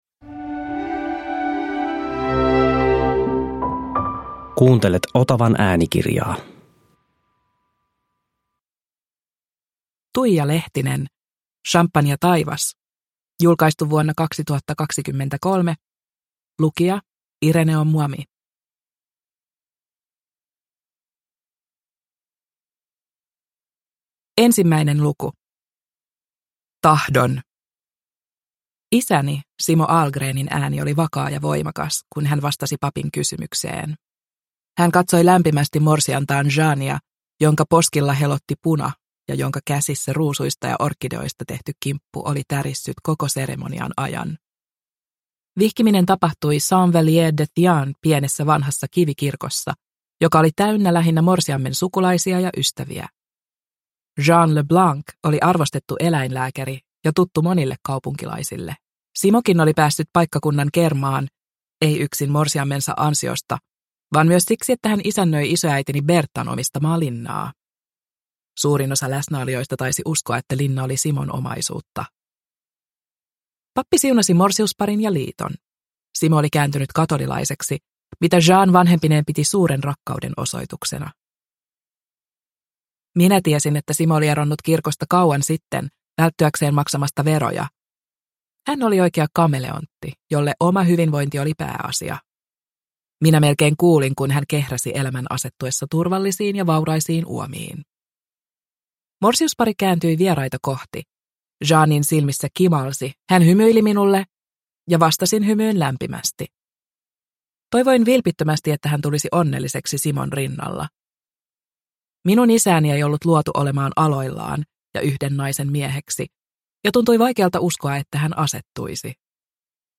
Samppanjataivas – Ljudbok – Laddas ner